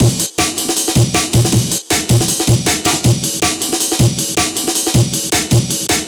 cw_amen05_158.wav